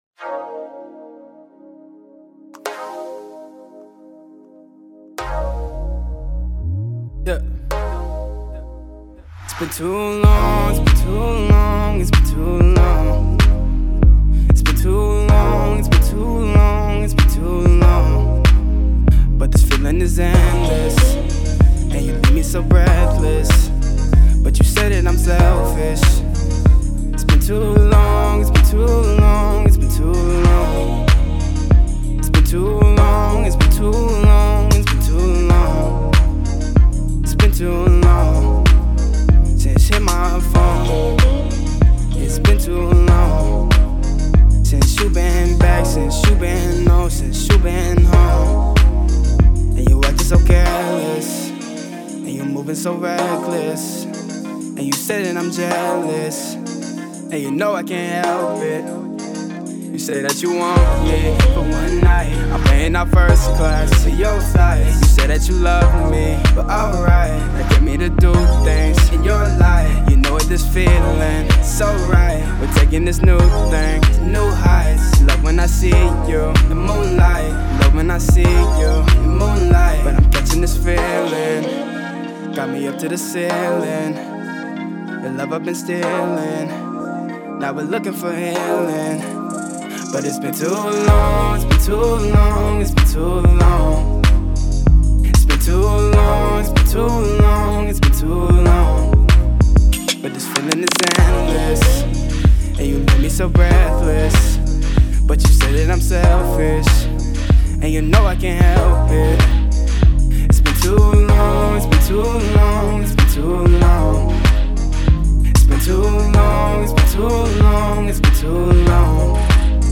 Description : Fits into Hip Hop/R&B/Pop sound